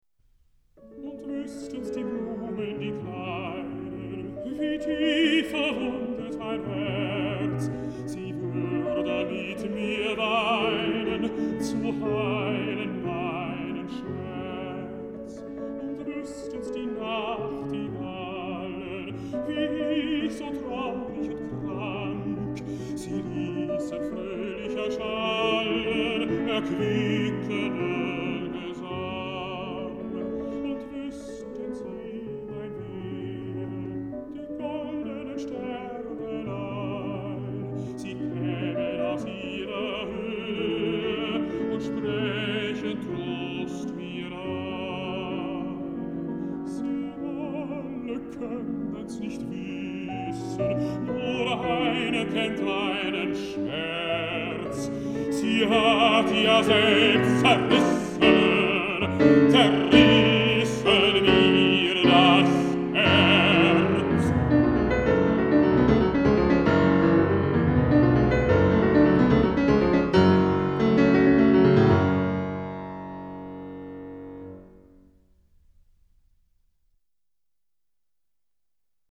Música vocal
Música clásica